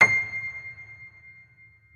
piano-sounds-dev
Vintage_Upright
c6.mp3